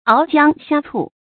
熬姜呷醋 áo jiāng xiā cù
熬姜呷醋发音
成语注音ㄠˊ ㄐㄧㄤ ㄍㄚ ㄘㄨˋ